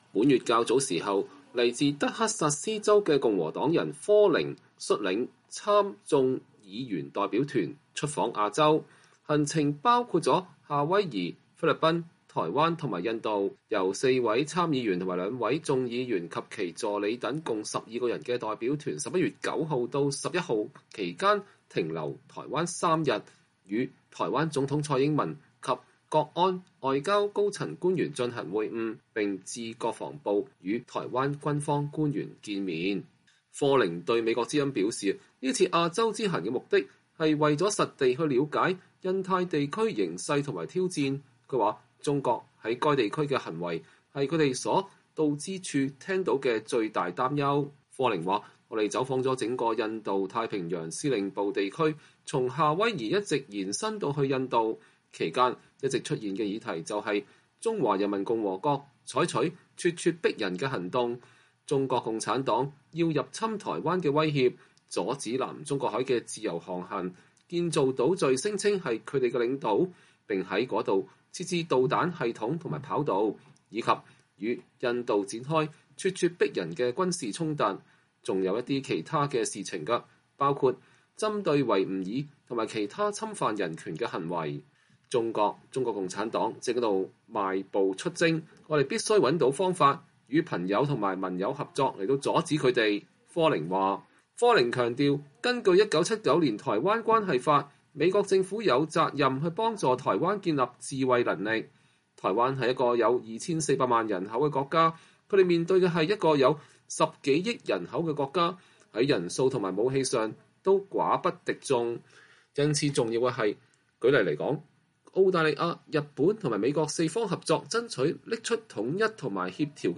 “中國、中國共產黨正在邁步出征，我們必須找到方法，與朋友和盟友合作來阻止他們，” 科寧11月16日在國會山接受美國之音採訪時說。